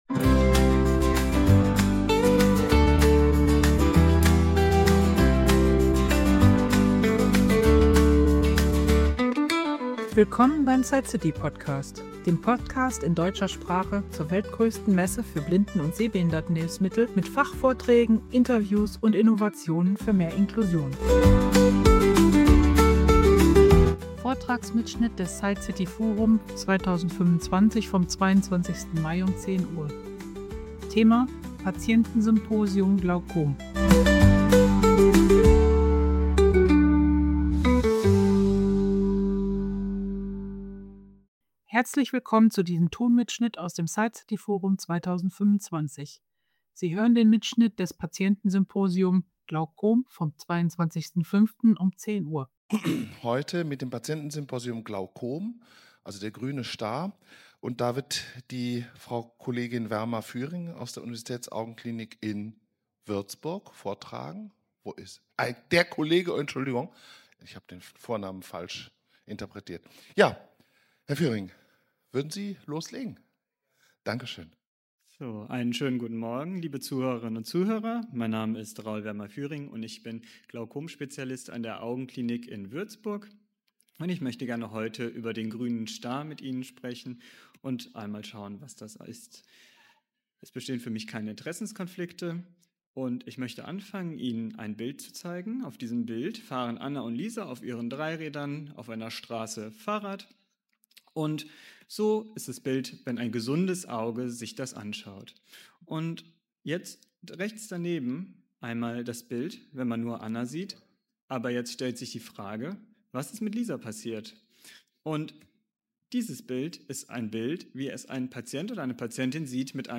SightCity Forum 2025 - Patientensymposium Glaukom (F2011) ~ SightCity DE Podcast
Die Zuhörer erhalten wertvolle Einblicke in aktuelle Forschung und Therapieoptionen sowie die Möglichkeit, ihre Fragen direkt an den Experten zu stellen.